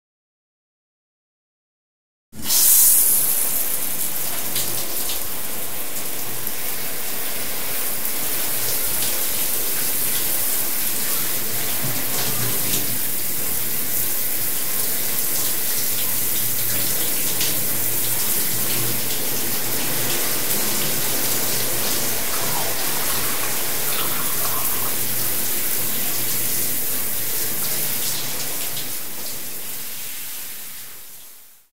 دانلود آهنگ دوش حمام 1 از افکت صوتی طبیعت و محیط
دانلود صدای دوش حمام 1 از ساعد نیوز با لینک مستقیم و کیفیت بالا
جلوه های صوتی